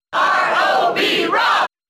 R.O.B.'s cheer in the US versions of Brawl.
R.O.B._Cheer_English_SSBB.ogg